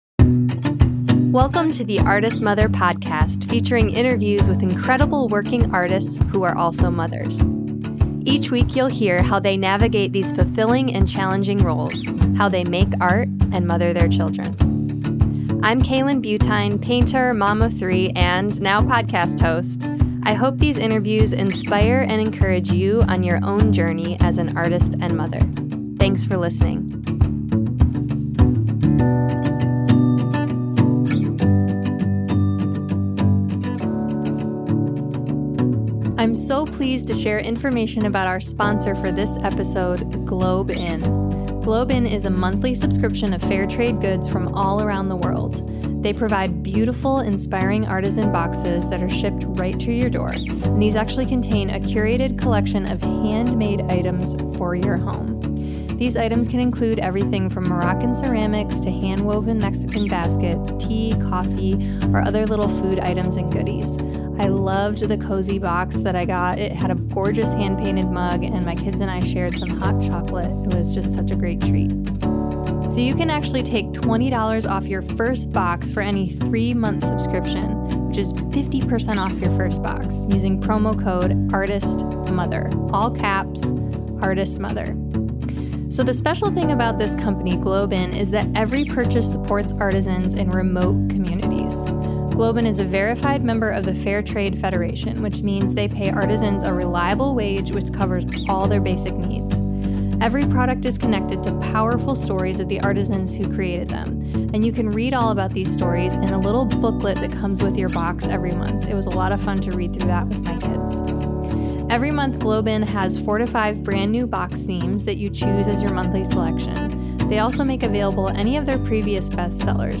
Thank you to everyone who sent in a voice memo + Happy Mother’s…
Tune in as we hear from many members of our community who share heartfelt and honest stories about their Moms.